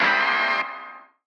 DDW Hit 2.wav